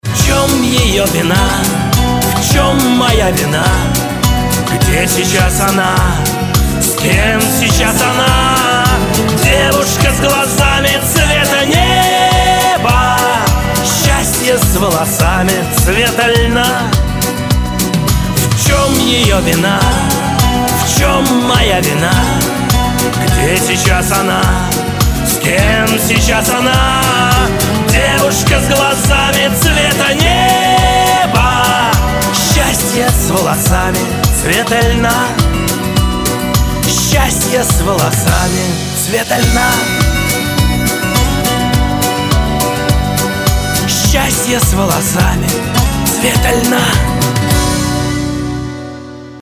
308 Категория: Нарезки шансона Загрузил